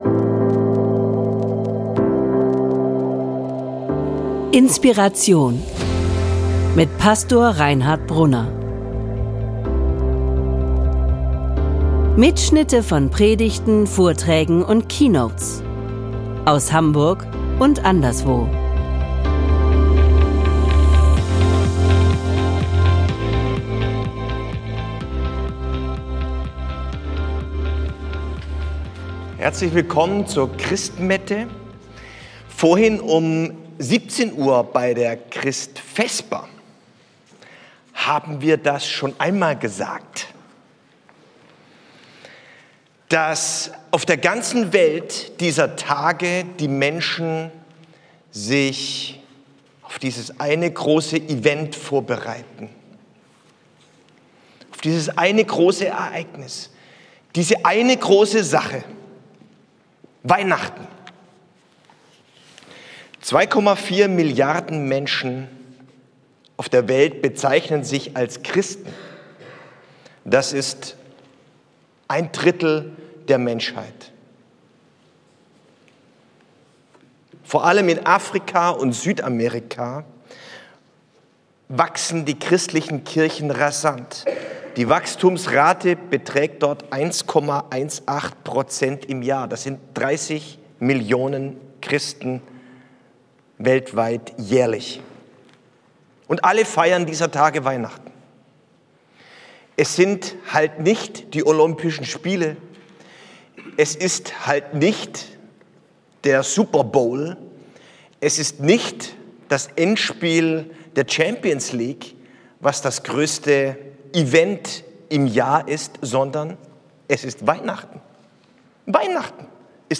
Darum geht es in dieser Weihnachtspredigt.